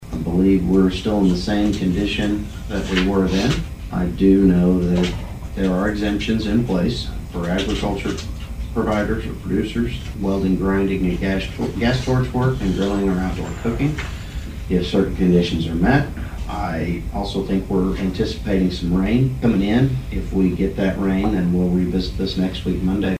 District 1 Commissioner Mitch Antle says weather conditions continue to support